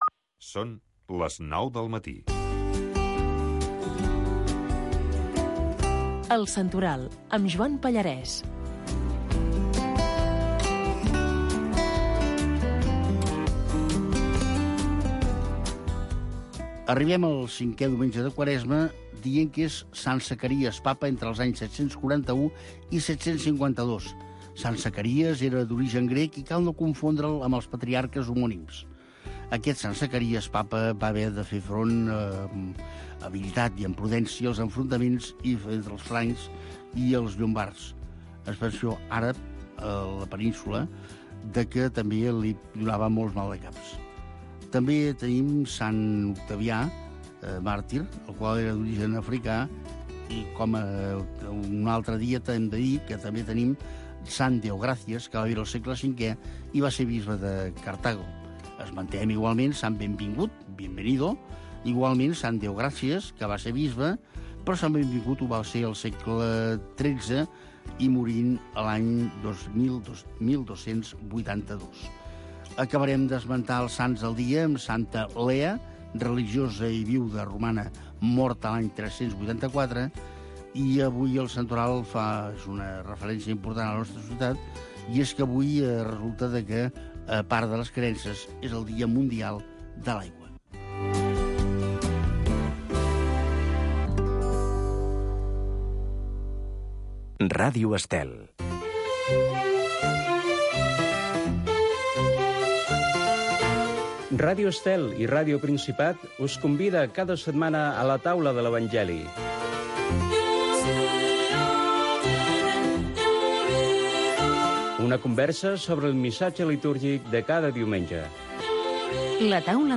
Espai per difondre la sardana.